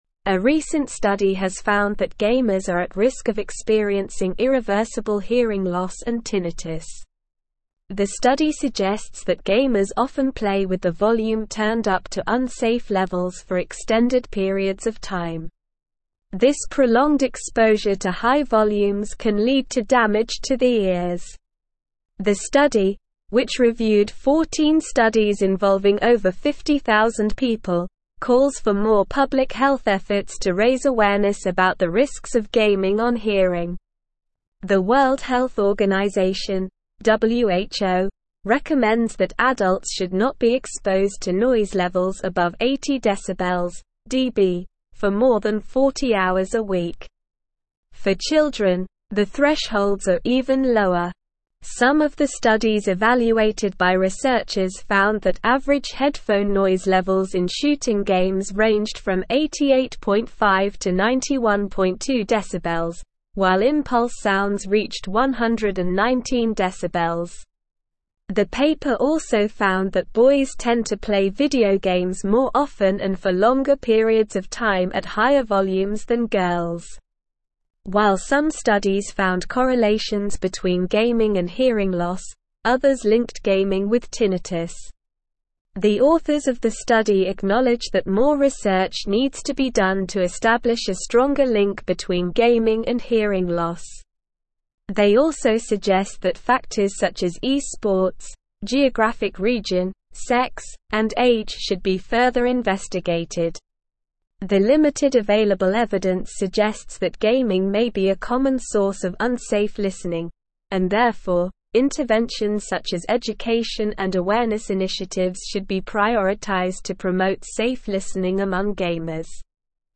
Slow
English-Newsroom-Advanced-SLOW-Reading-Gamers-at-Risk-of-Hearing-Loss-and-Tinnitus.mp3